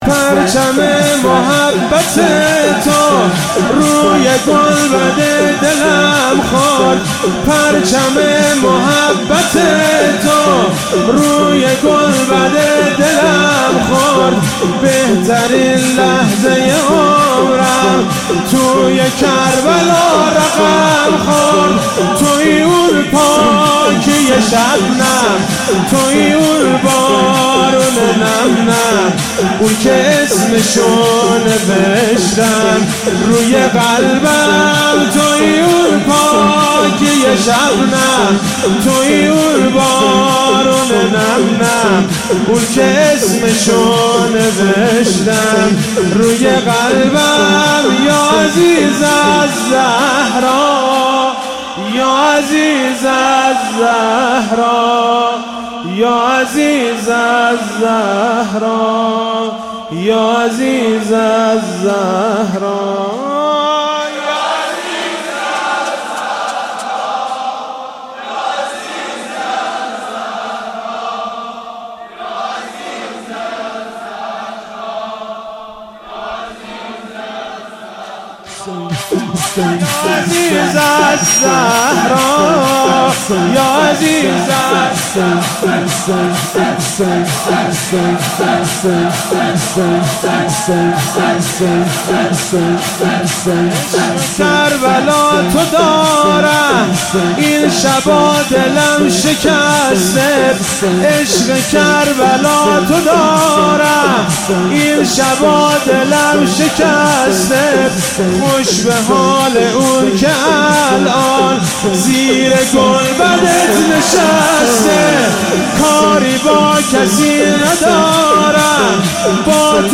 محرم 95
شور